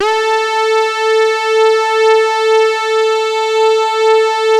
69-TARKUS A3.wav